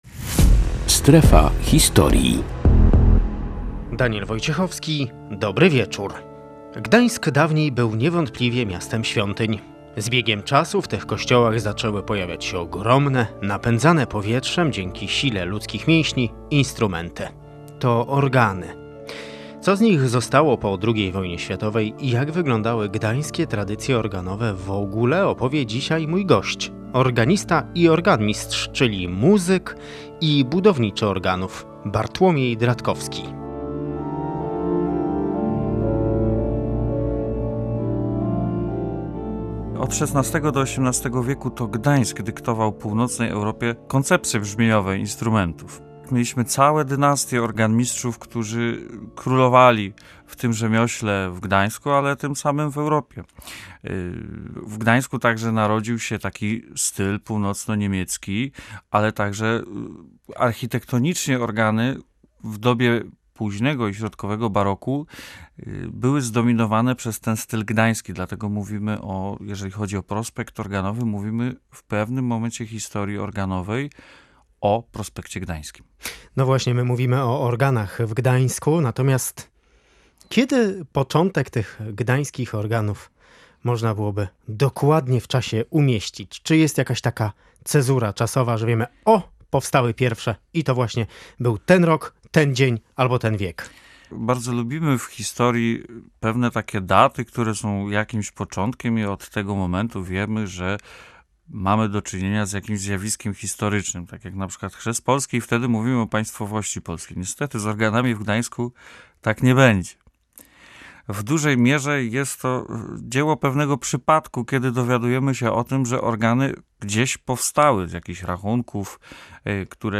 Estetyka i jakość gdańskich organów. Rozmowa o budowniczych monumentalnych instrumentów